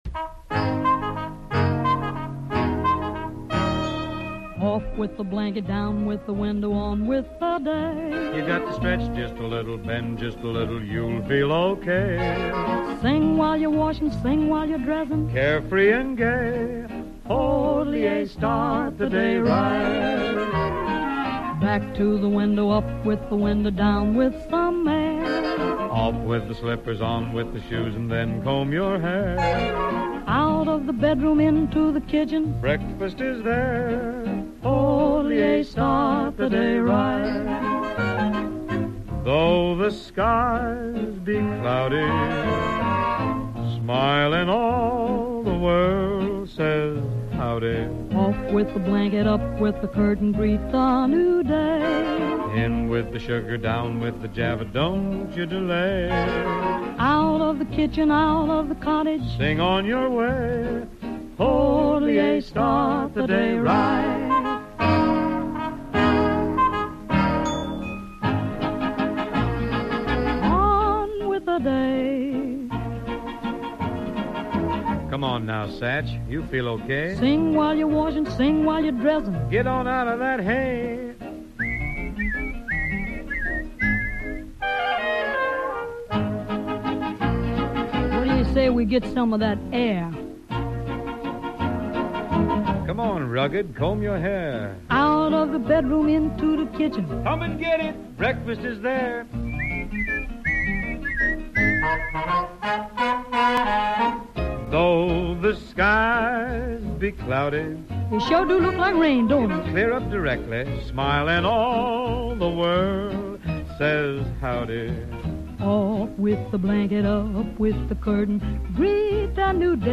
popular music from pre rock & roll eras